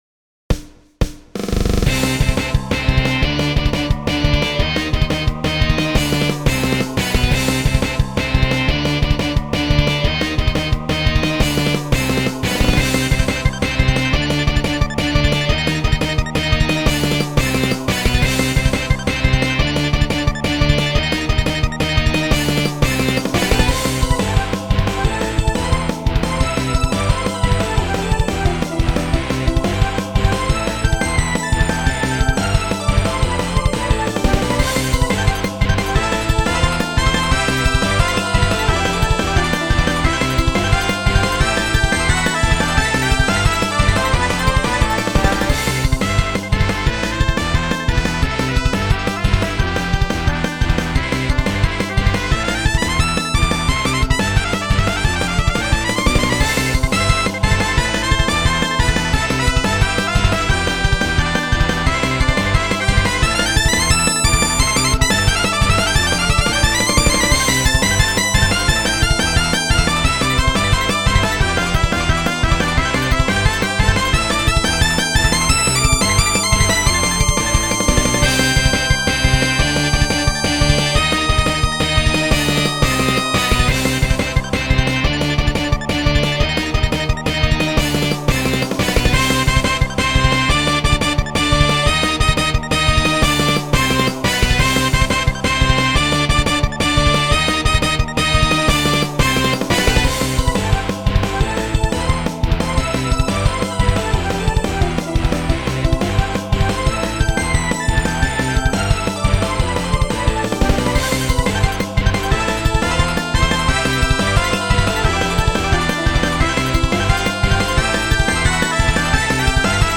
説明元気はあるけど天使感はありません